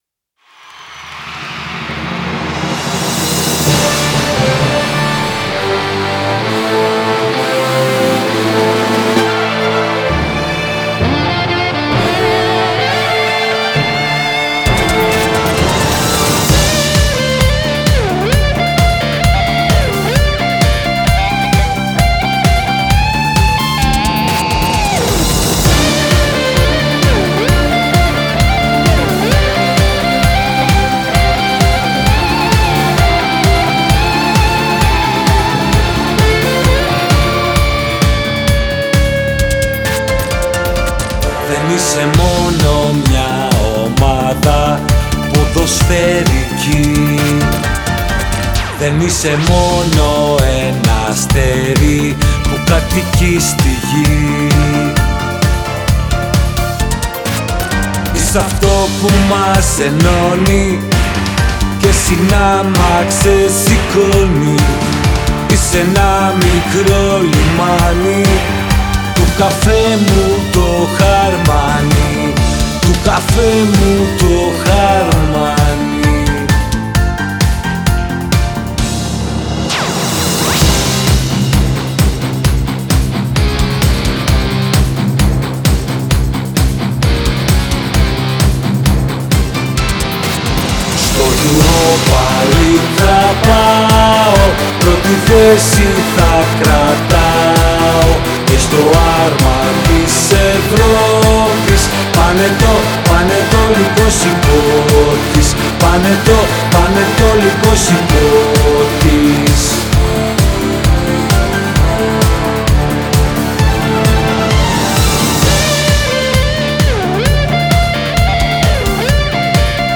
Ηλεκτρική Κιθάρα